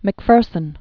(mĭk-fûrsən), Aimee Semple 1890-1944.